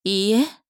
大人女性│女魔導師│リアクションボイス